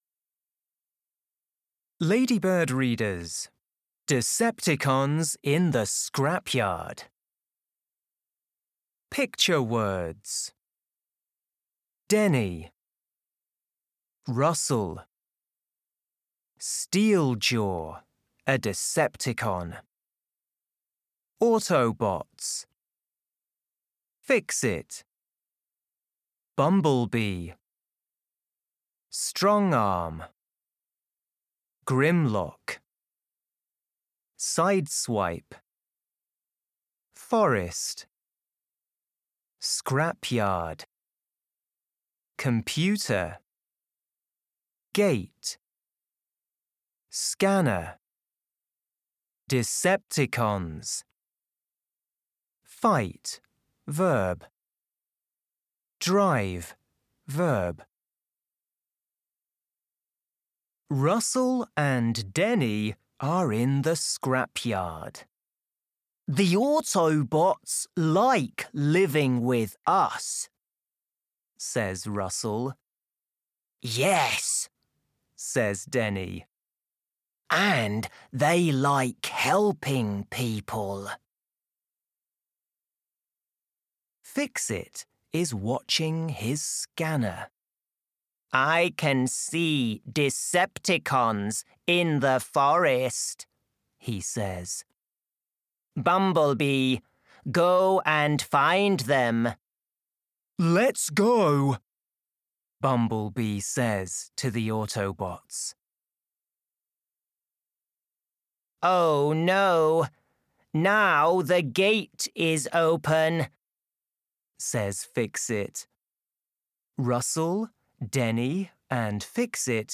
Audio UK
Reader - Ladybird Readers